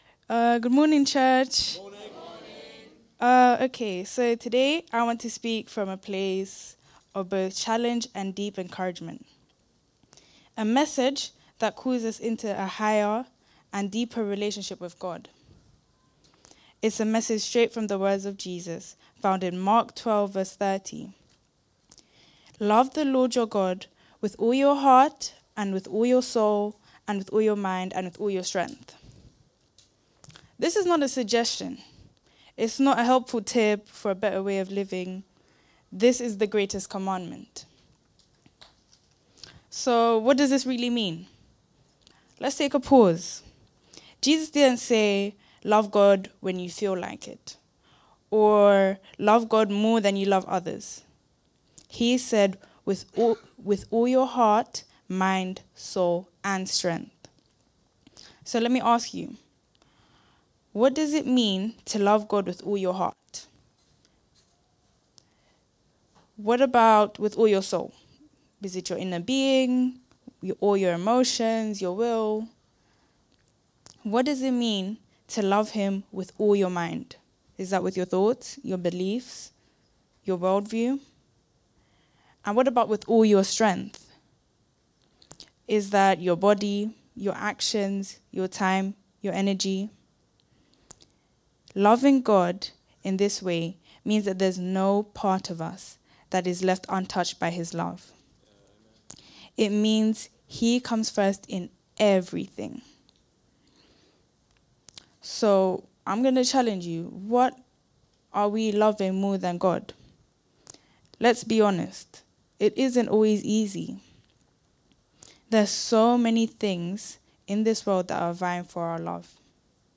| Sermons at Trinity Church